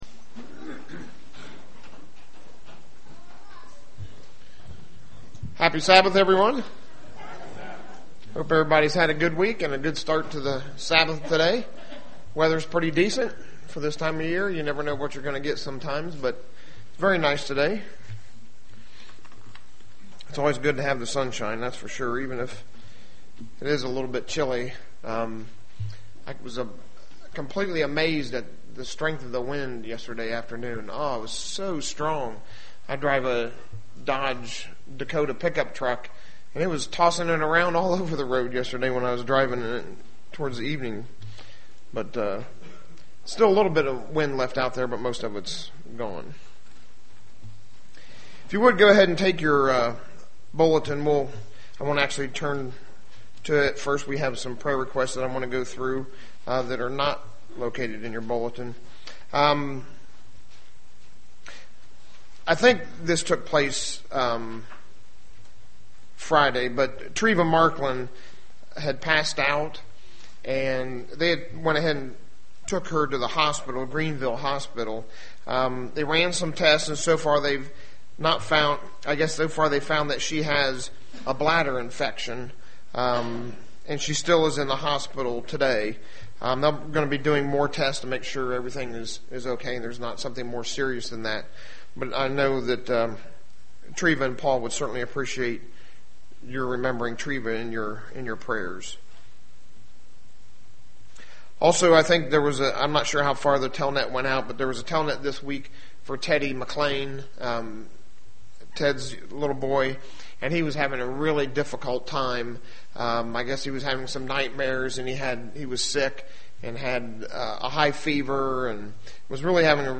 The Bible has a lot to say about money. This sermon depicts a few of the lessons the Bible has on money
Given in Dayton, OH